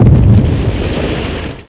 blast.mp3